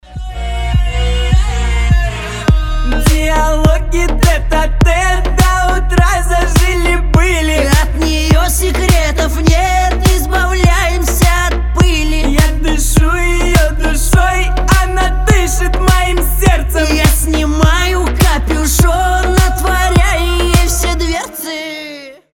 • Качество: 320, Stereo
поп
мужской вокал
дуэт